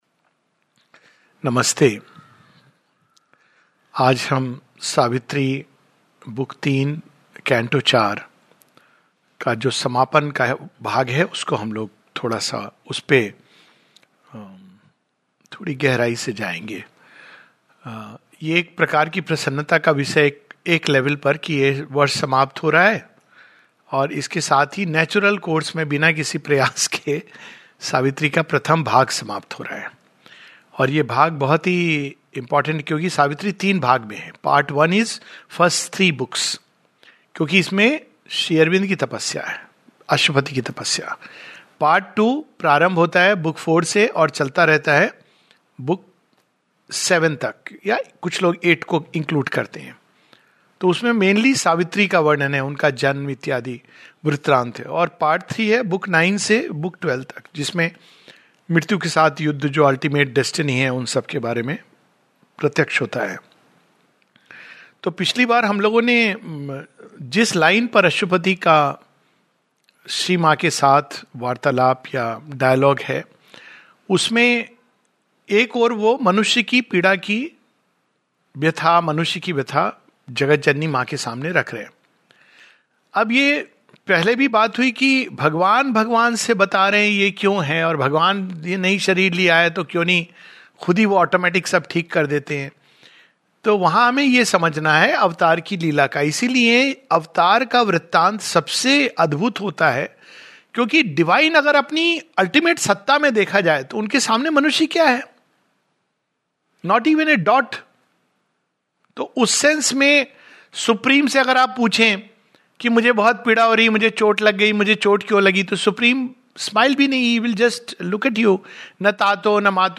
This talk is based on the concluding portion of Savitri Book Three Canto Four.